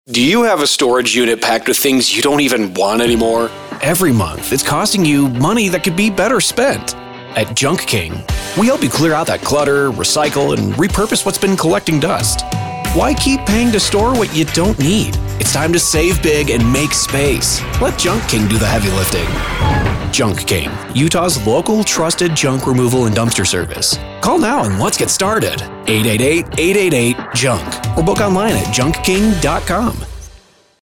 A youthful, millennial voice artist here to get your story out there!
Young Adult
If you're looking for that youthful, millennial sound I'm your guy!